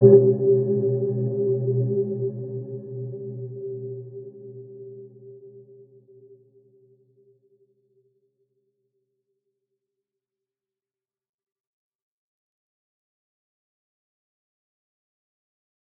Dark-Soft-Impact-G4-mf.wav